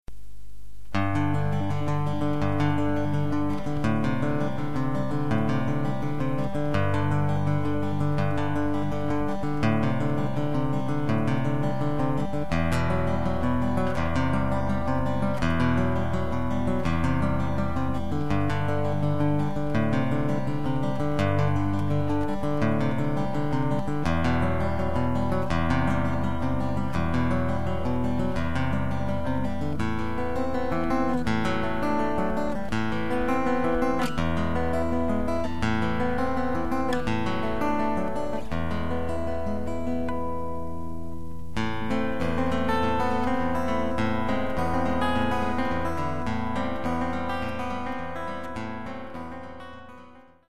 A great moment of  Picking style music.